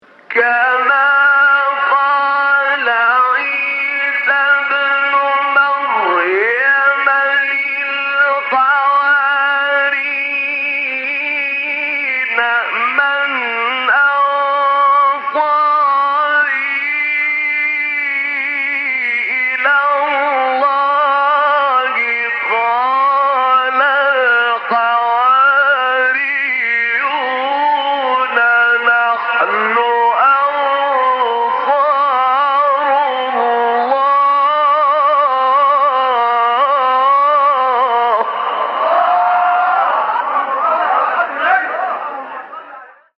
مقام صبا عبدالفتاح طاروطی | نغمات قرآن | دانلود تلاوت قرآن